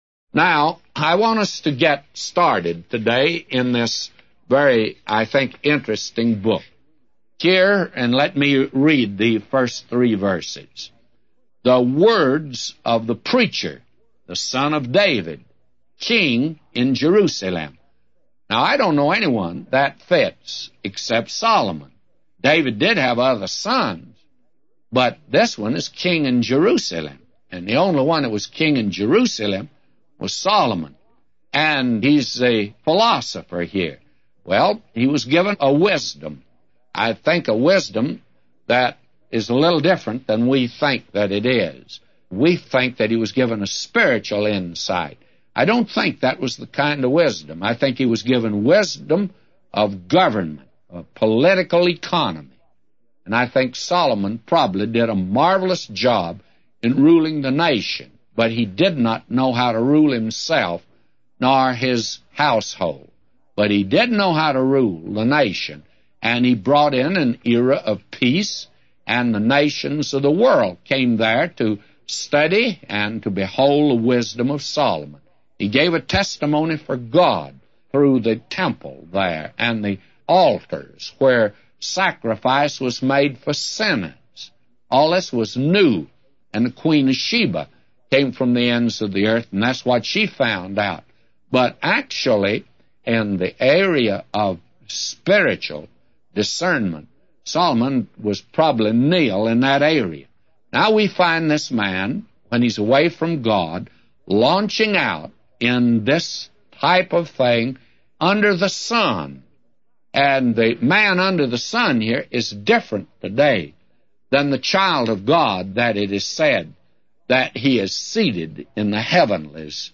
A Commentary By J Vernon MCgee For Ecclesiastes 1:1-999